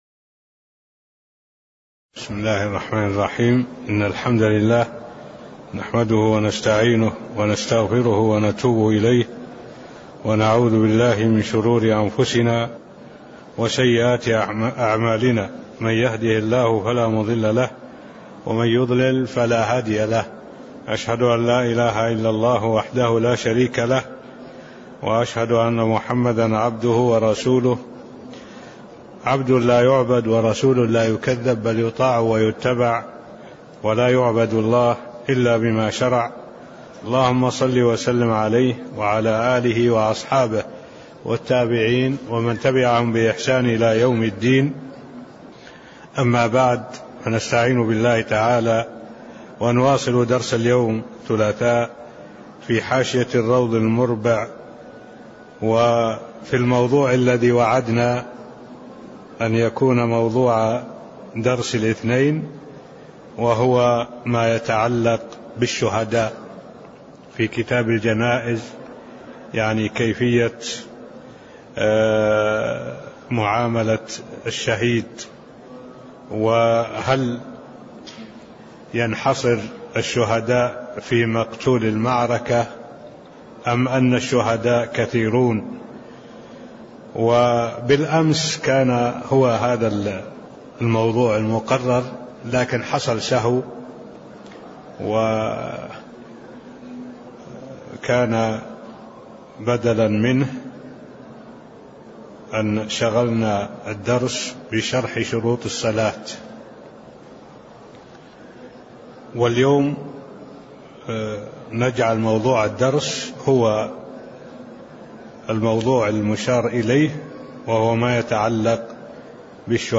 تاريخ النشر ٢٠ محرم ١٤٢٩ هـ المكان: المسجد النبوي الشيخ: معالي الشيخ الدكتور صالح بن عبد الله العبود معالي الشيخ الدكتور صالح بن عبد الله العبود شهيد المعركة (009)